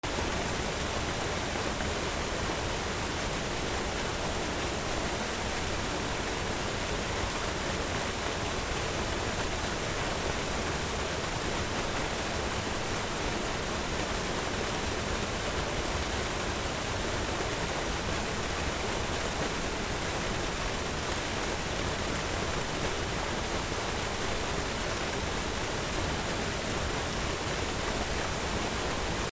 滝
/ B｜環境音(自然) / B-15 ｜水の流れ